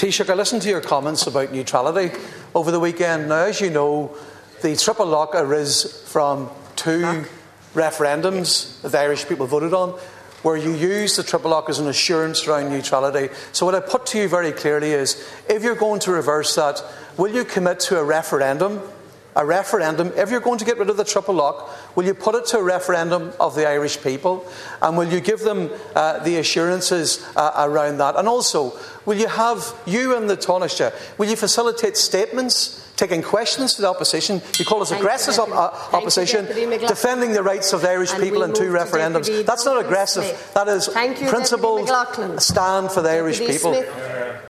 Deputy Padraig MacLochlainn told the Dail the people should have the final say…………..